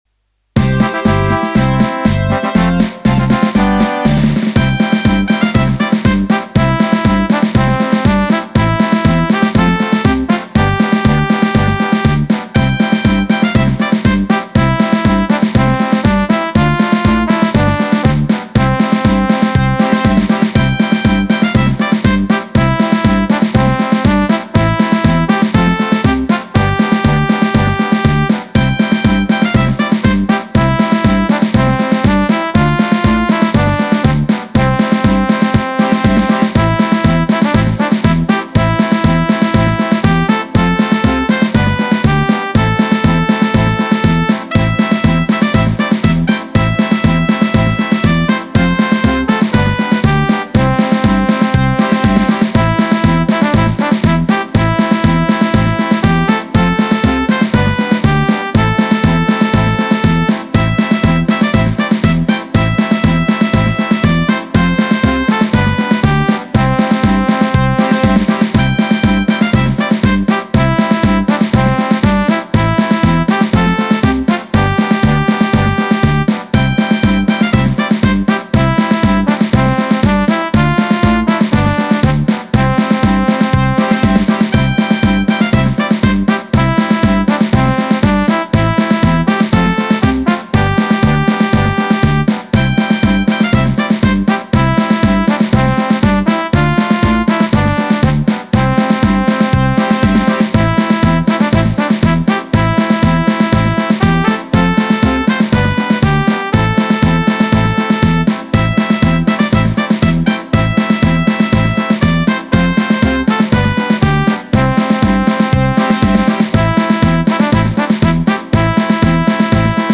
Een klassiek ritme met karakter, waar het echt leuk op bouwen was.
De basis zit goed, de sfeer klopt, en het geheel heeft die typische Traditional-drive. Alleen de trompet mocht voor mij nog wat meer naar voren komen — die blijft nu net iets té bescheiden op de achtergrond.
Doeltempo: 120 BPM